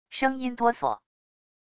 声音哆嗦wav音频